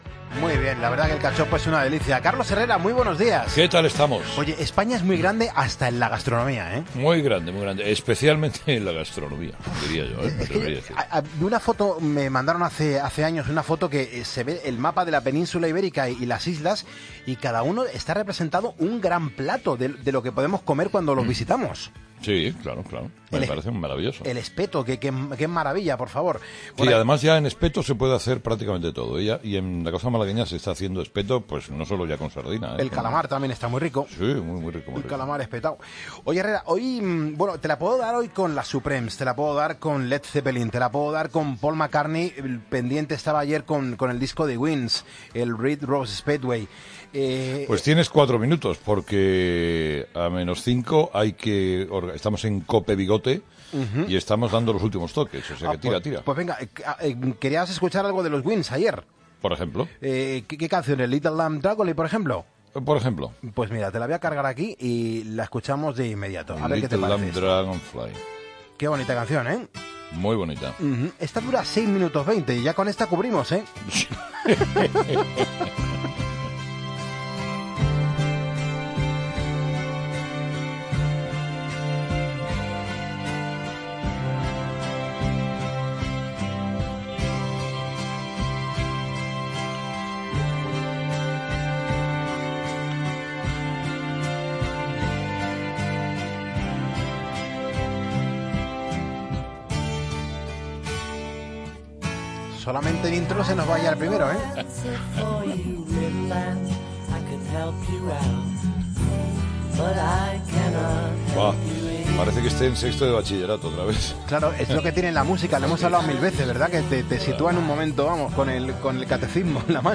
Hoy solo tenemos cinco minutos..Estamos en COPE Bigote en Sanlucar